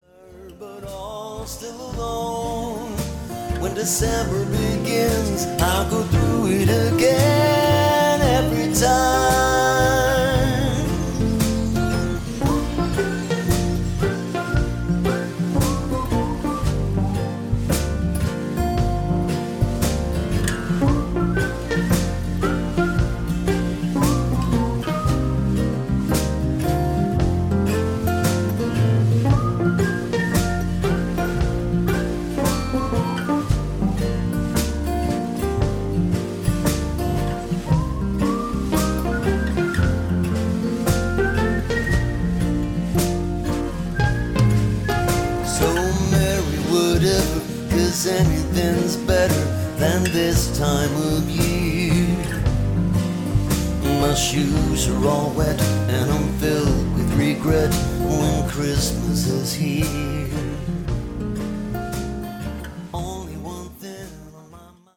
• Julehits
med sin smørbløde crooner-stemme, akkompagneret af enten sin guitar eller sit klaver. De gode gamle samt nyere julesange.